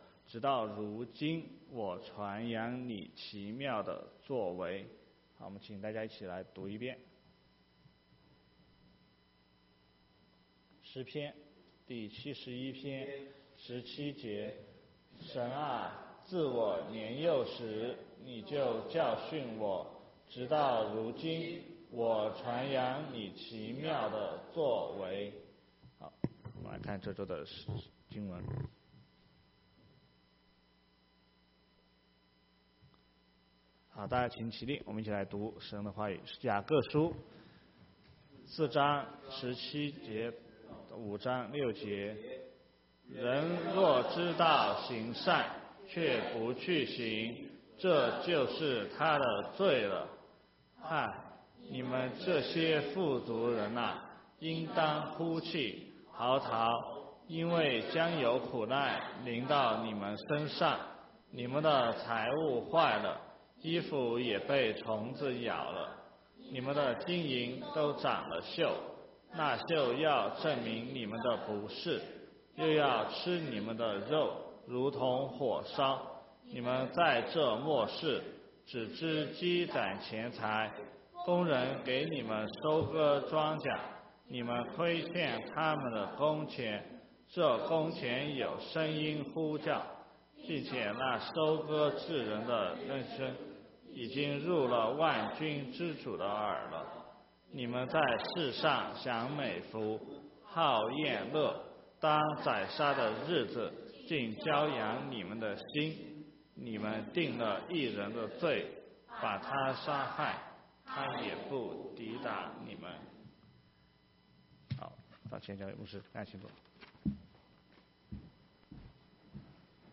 Sermon 6/24/2018